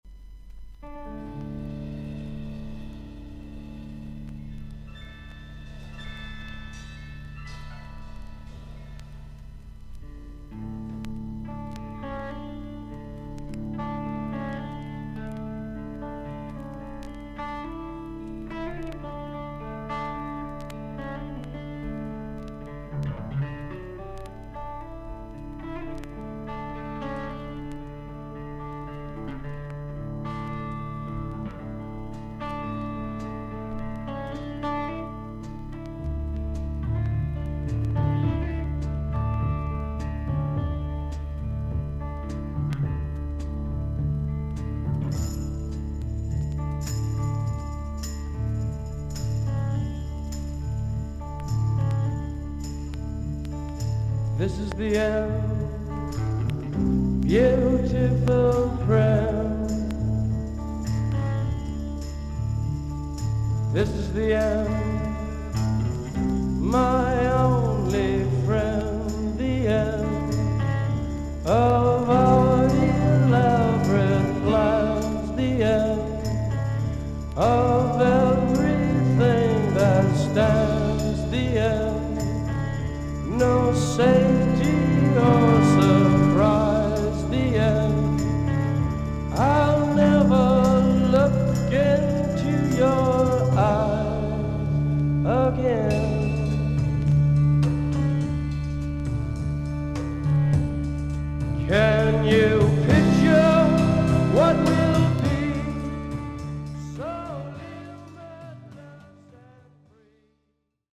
少々軽いパチノイズの箇所あり。クリアな音です。B面に少サーフィス・ノイズの箇所あり。